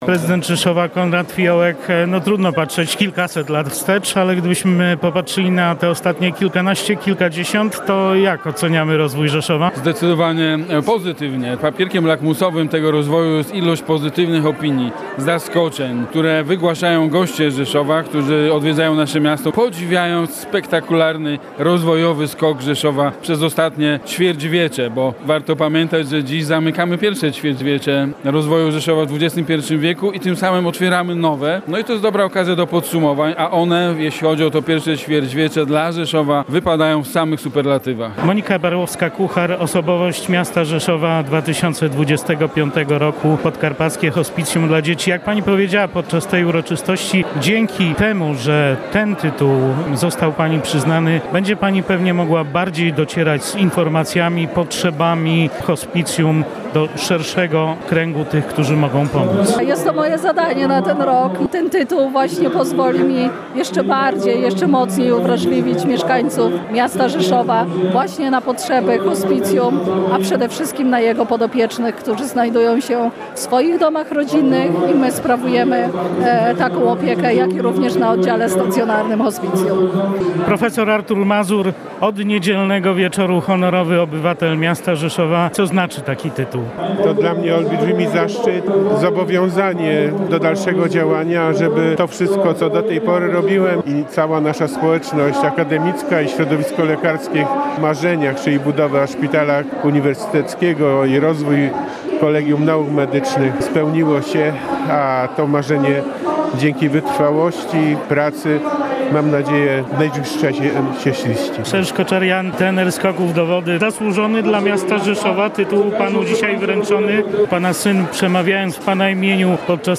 672. rocznica lokacji Rzeszowa uczczona w Filharmonii Podkarpackiej [ZDJĘCIA] • Relacje reporterskie • Polskie Radio Rzeszów
Relacje reporterskie • Okolicznościowym tortem, nadaniem tytułów osobom zasłużonym dla Rzeszowa oraz rocznicową uroczystością uczczono wczoraj (18.01) w rzeszowskiej Filharmonii 672. rocznicę lokacji stolicy Podkarpacia.